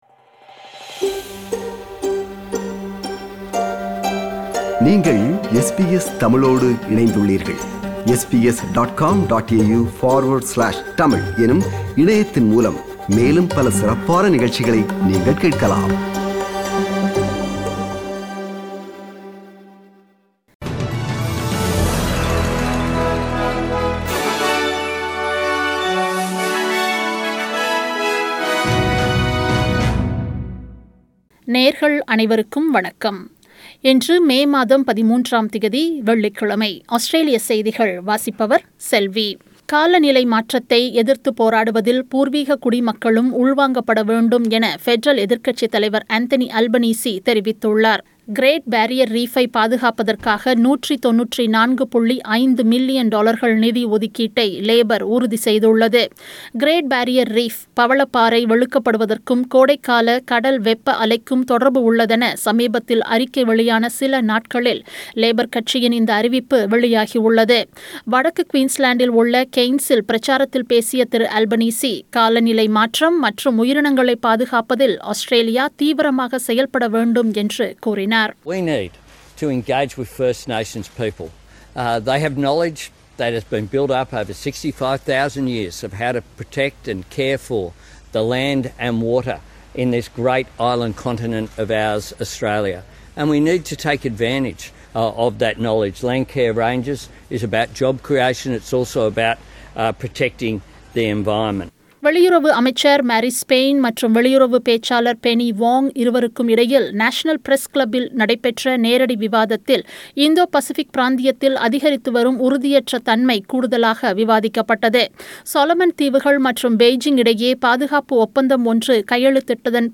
Australian news bulletin for Friday 13 May 2022.